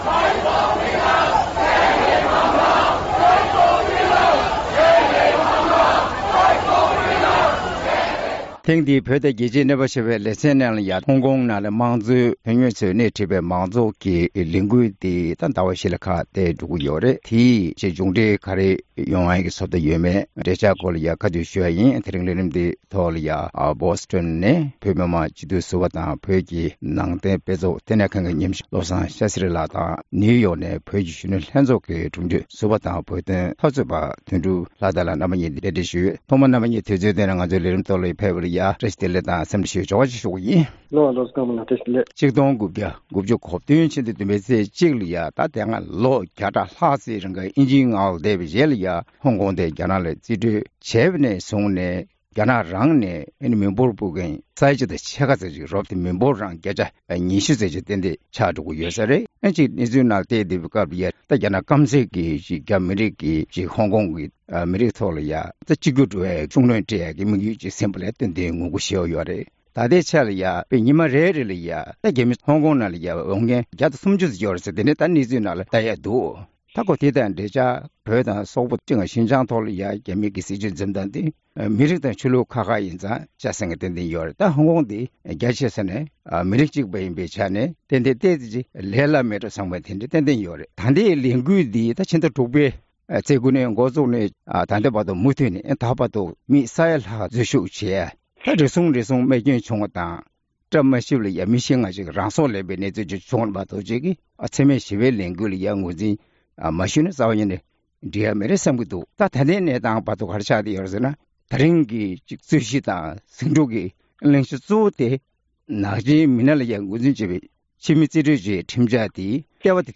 བོད་དང་རྒྱལ་སྤྱིའི་གནས་བབ་ཞེས་པའི་ལེ་ཚན་ནང་། ཧོང་ཀོང་ནང་མི་མང་གི་ལས་འགུལ་སྤེལ་ནས་ད་ཆ་ཟླ་བ་བཞི་ཙམ་འགྲོ་ཡི་ཡོད་པར་བརྟེན། དེའི་མ་འོངས་པའི་འབྱུང་འབྲས་སོགས་ཀྱི་སྐོར་འབྲེལ་ཡོད་ཁག་ཅིག་དང་གླེང་མོལ་ཞུས་པ་གསན་རོགས་གནང་།